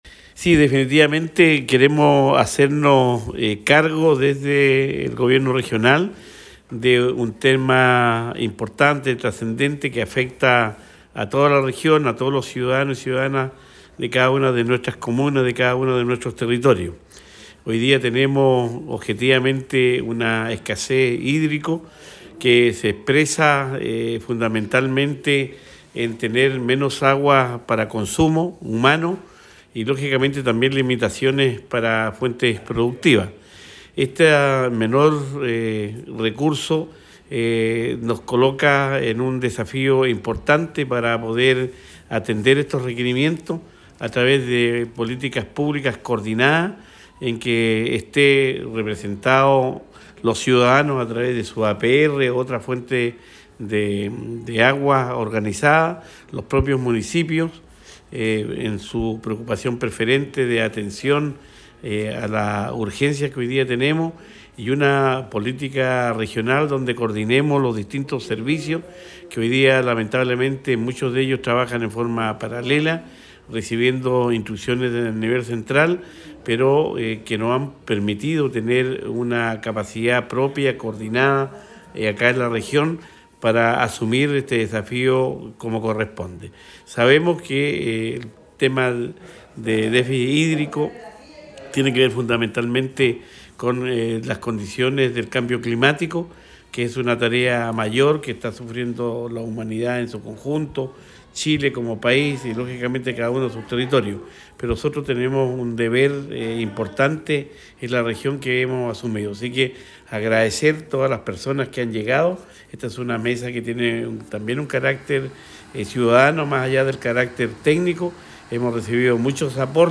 El encuentro se llevó a cabo en el Auditorio de la Universidad Santo Tomás de Valdivia y estuvo encabezada por el Gobernador Regional Luis Cuvertino junto a los Consejeros Regionales Catalina Hott y Matías Fernández; además participó el Senador Alfonso De Urresti; la Asociación de Municipalidades, integrada por la alcaldesa Carla Amtmann, el alcalde Aldo Retamal y el alcalde Miguel Carrasco, entre otras autoridades regionales académicas y de la comunidad científica, organizaciones de la sociedad civil, representantes de comités de APR, ONG’s y asociaciones ligadas a la defensa del agua, entre otras.
Cuña_Gobernador-Cuvertino_Mesa-del-Agua.mp3